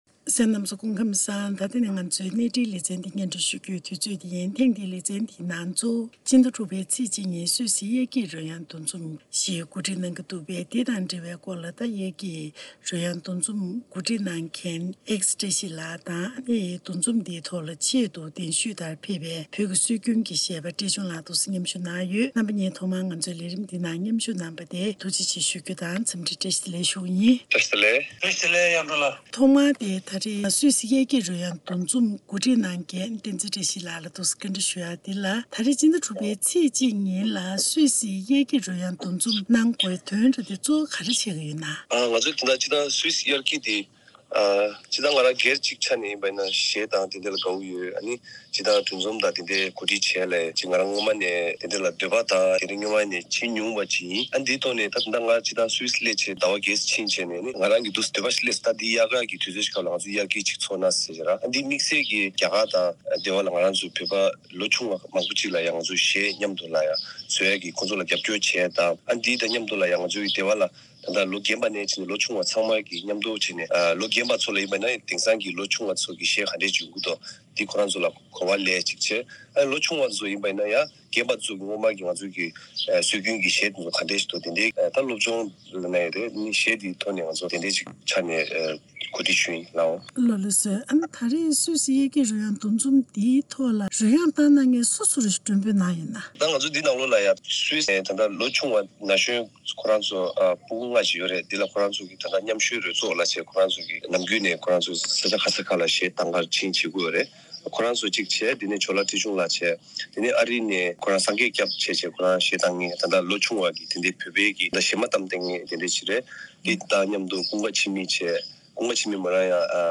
ཐད་ཀར་གནས་འདྲི་ཞུས་པ་ཞིག་གསན་རོགས་གནང་།